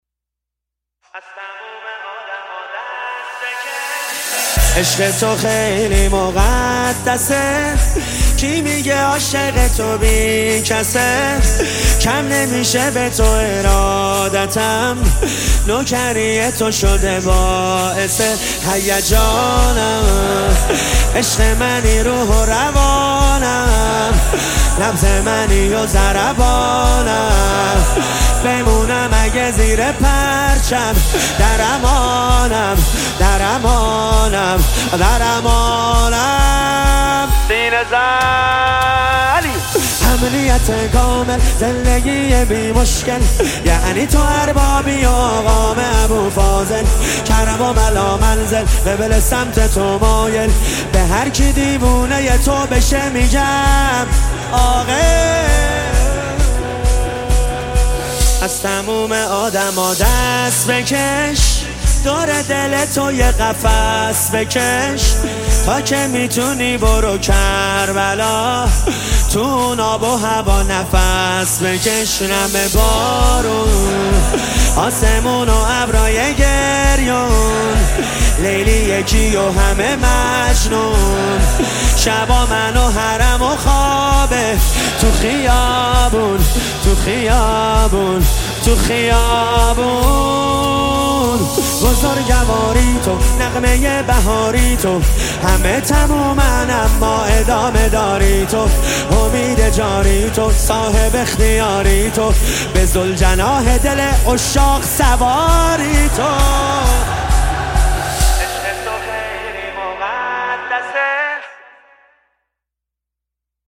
با فضایی معنوی و عاشقانه
با لحنی آرام و پرشور